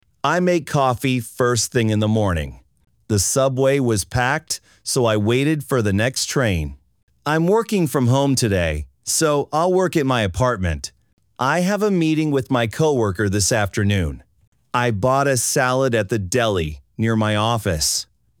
（シャドーイングに最適）